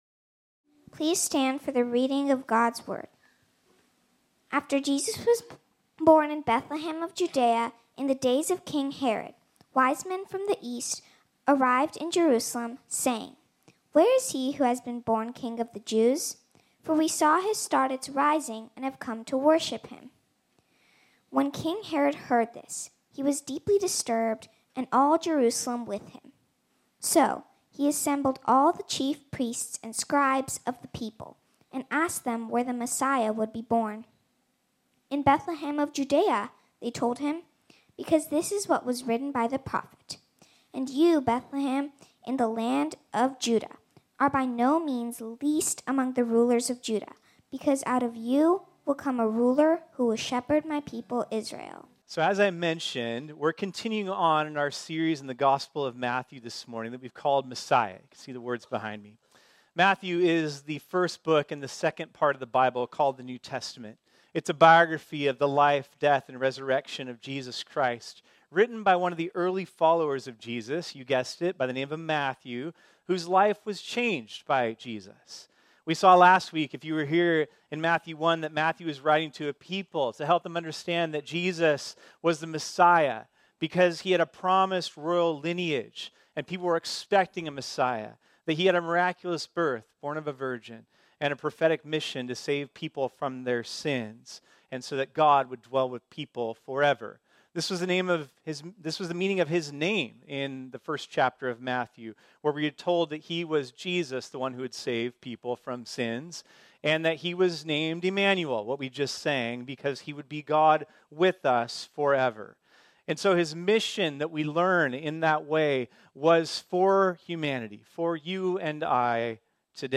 This sermon was originally preached on Sunday, December 24, 2023.